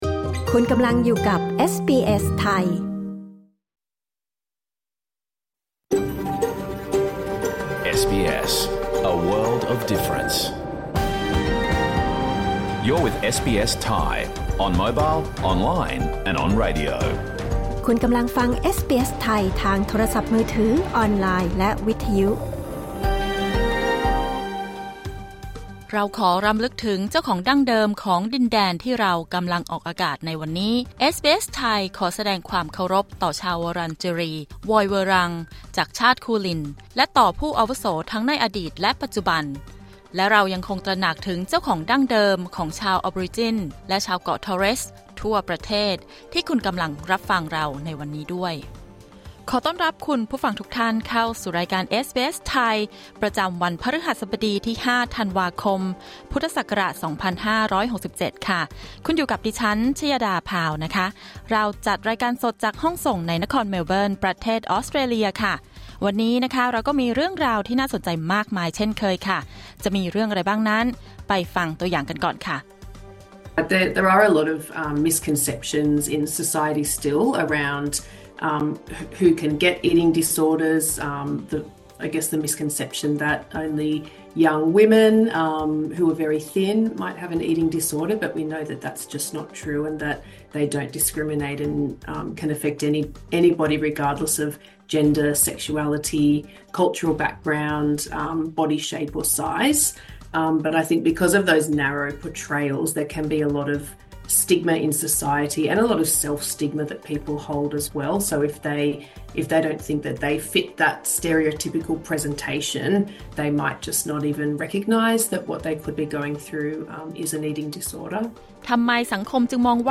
รายการสด 5 ธันวาคม 2567